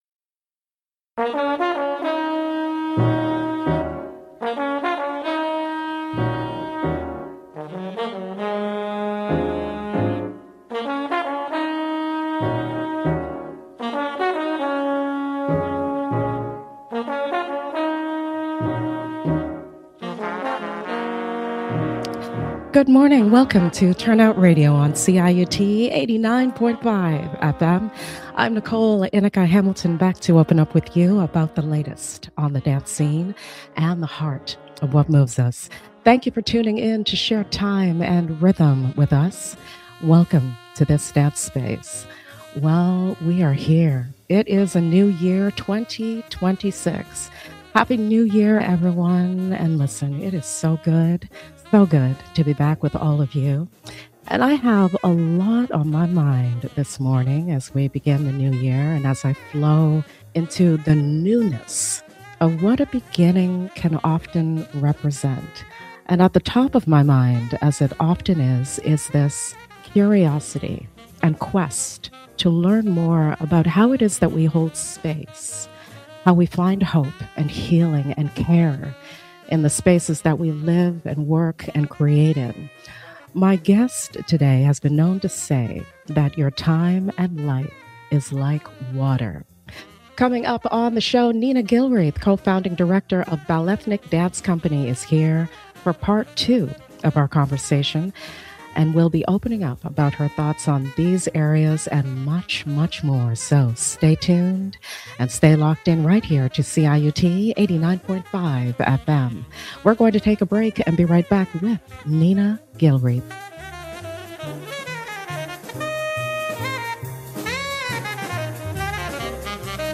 L ive on CIUT 89.5 FM